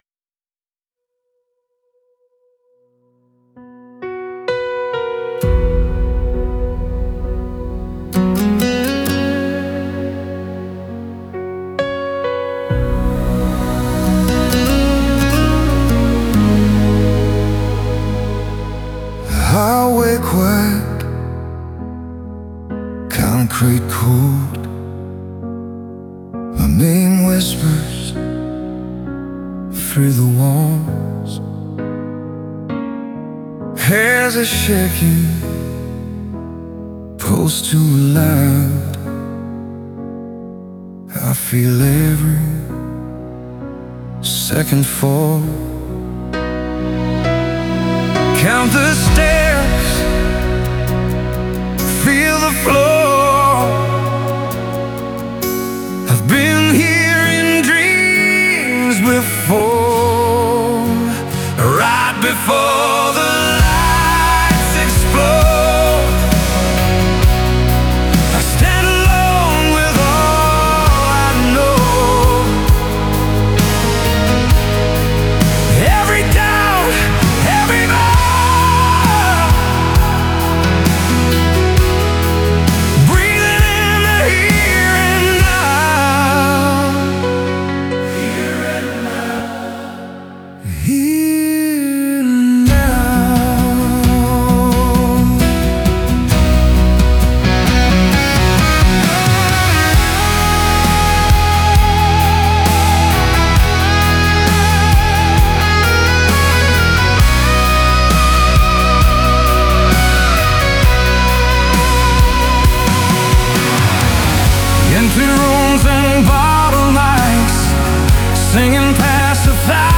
Before The Lights Open(Arena Ballad)
オリジナル曲♪